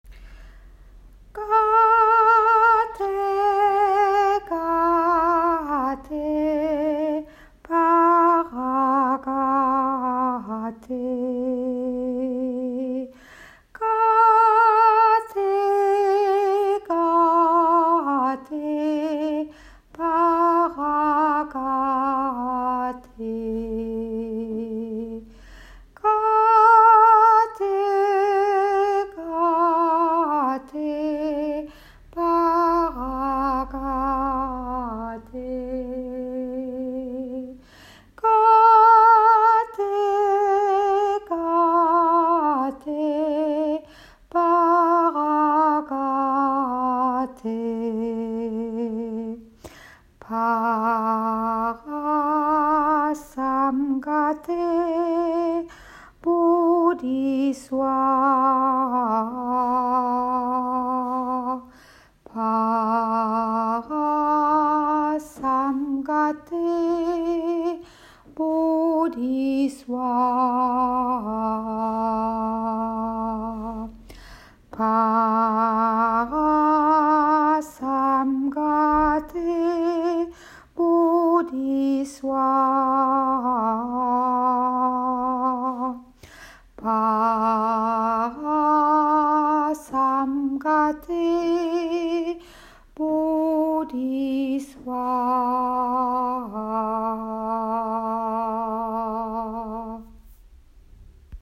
Le mantra  de l’impermanence  ( ou sutra du coeur) « Gate gate, paragate, para samgate bodhi swaha » comme nous le chantons pour traverser plus sereinement  ces temps de crises :